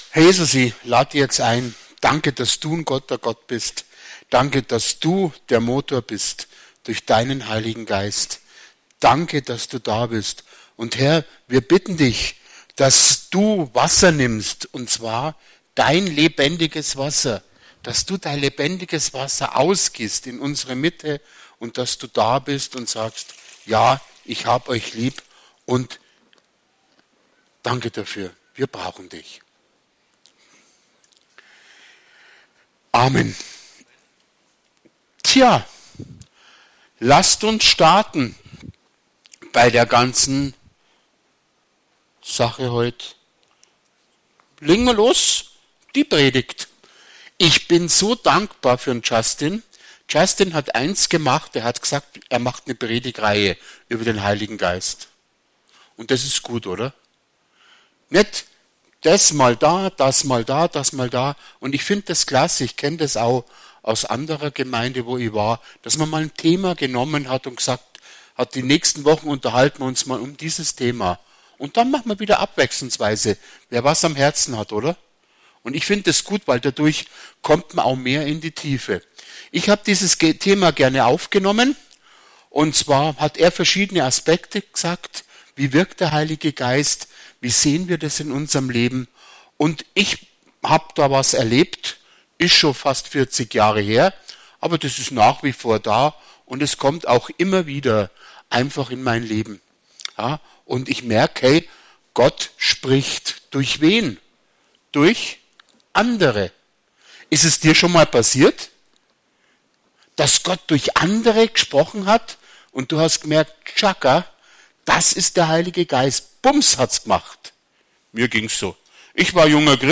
Predigt vom 09.03.2025 – Christliches Zentrum Günzburg